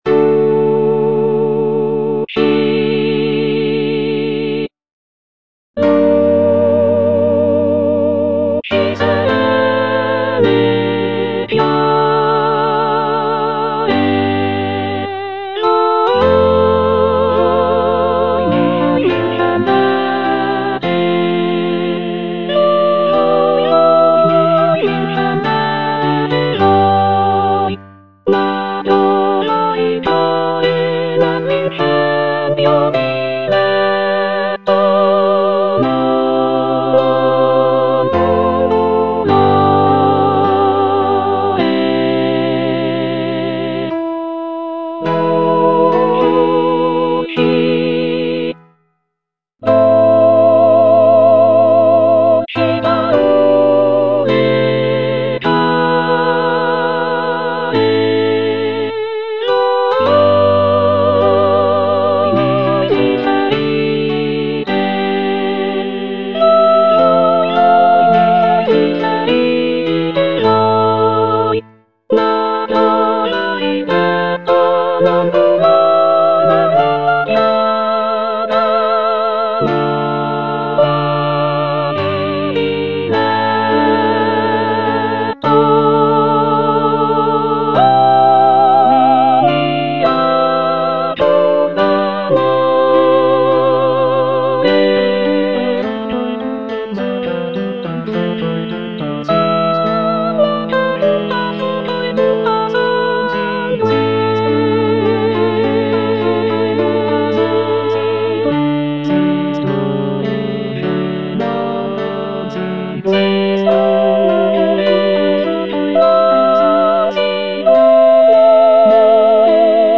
C. MONTEVERDI - LUCI SERENE E CHIARE Soprano I (Emphasised voice and other voices) Ads stop: auto-stop
"Luci serene e chiare" is a madrigal composed by Claudio Monteverdi, one of the most important figures in the development of Baroque music.
The madrigal is known for its intricate vocal lines and rich textures, creating a sense of serenity and clarity in the music.